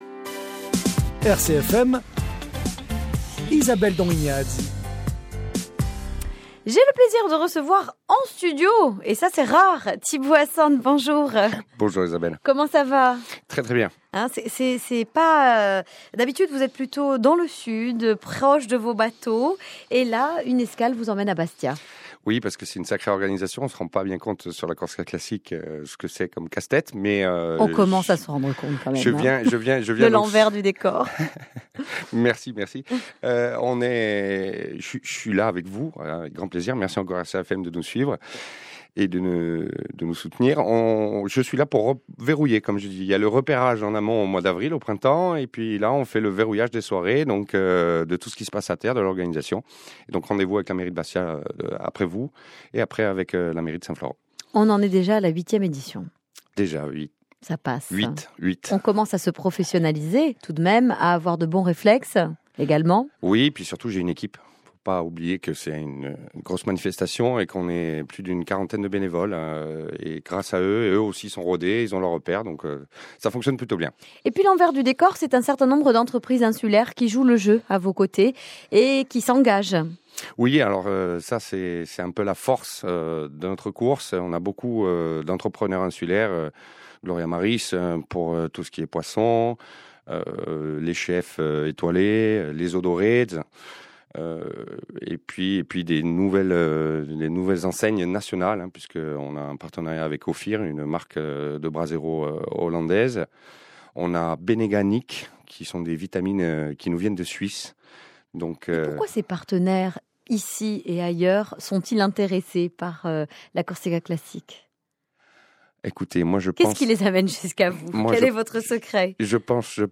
ITV live